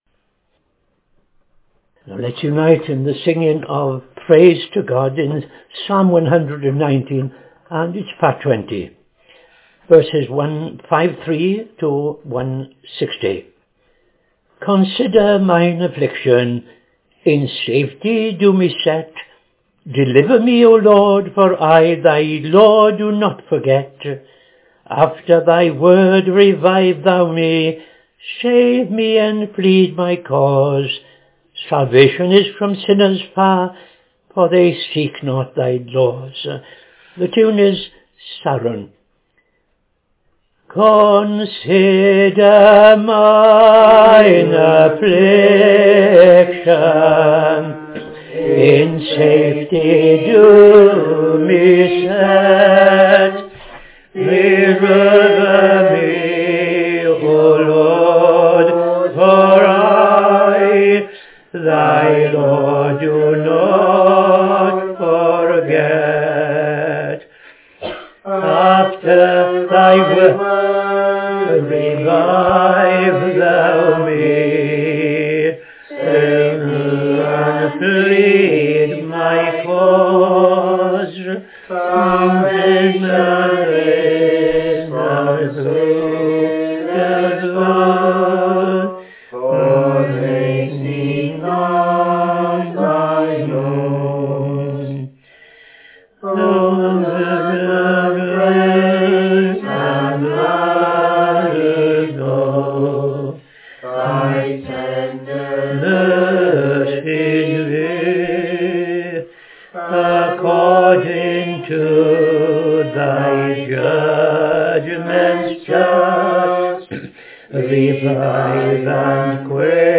Evening Service - TFCChurch
5.00 pm Evening Service Opening Prayer and O.T. Reading I Chronicles 27:1-15